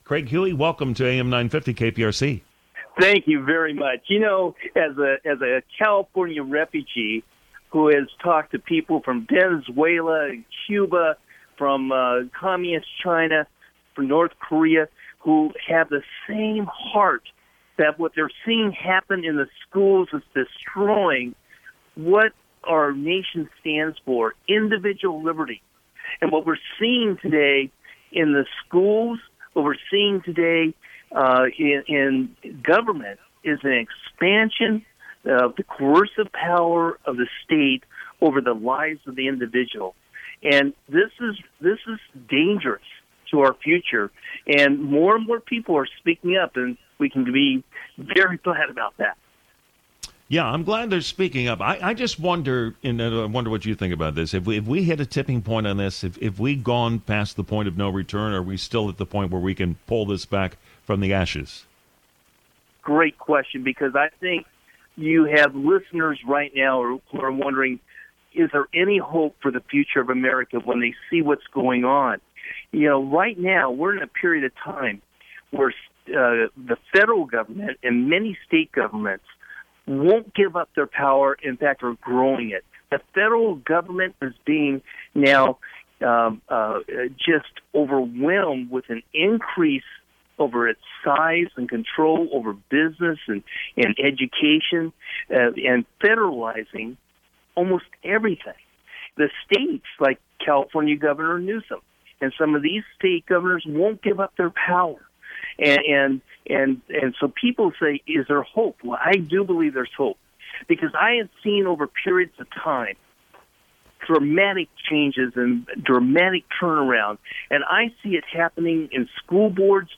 You can listen to the short 17-minute interview by clicking here.